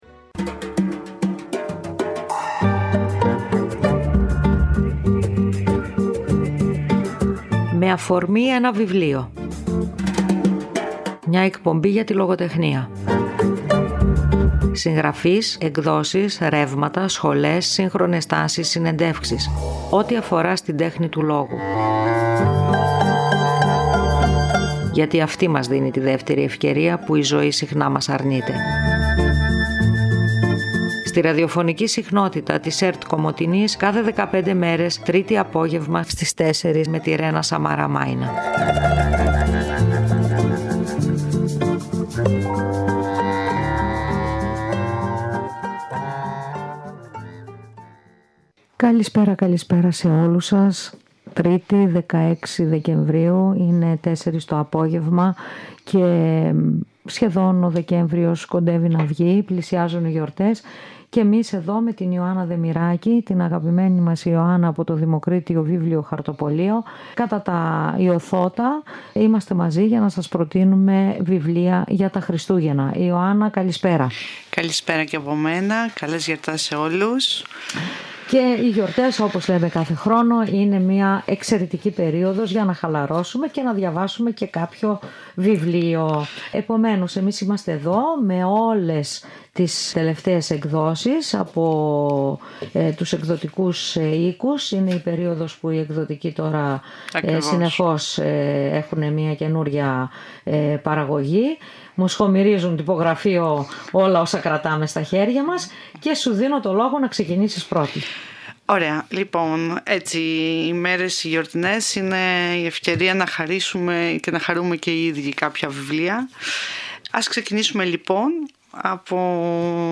Μια εκπομπή για το βιβλίο και τη λογοτεχνία.